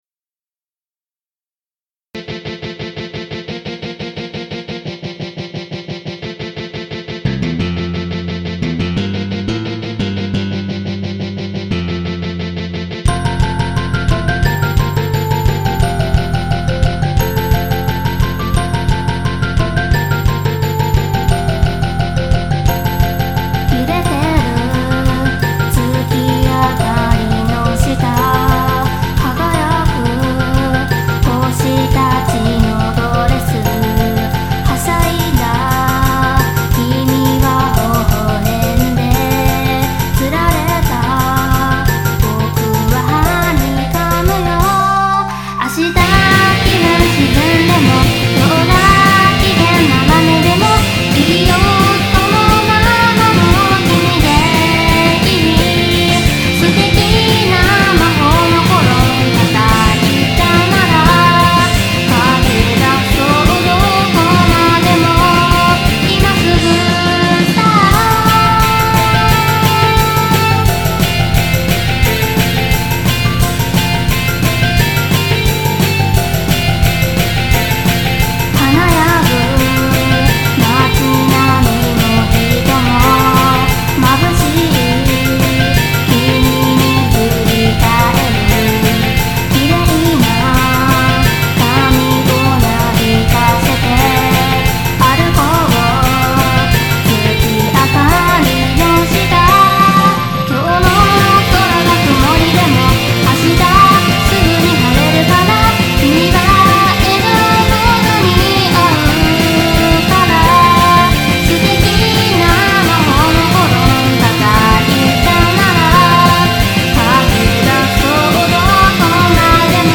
Vocal(Tr.4)